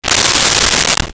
Shower Curtain Close 02
Shower Curtain Close 02.wav